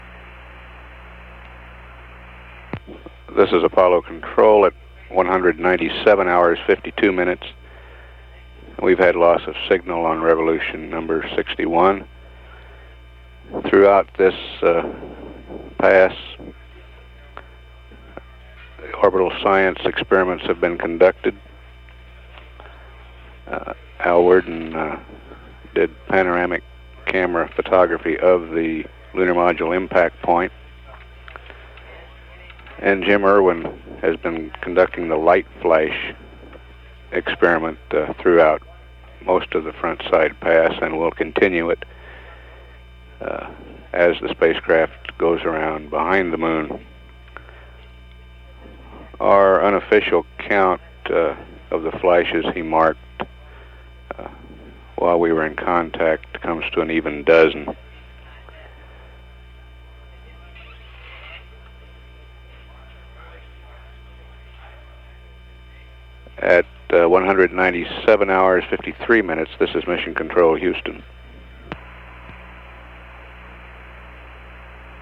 Very long comm break.